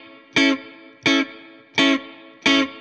DD_StratChop_85-Amaj.wav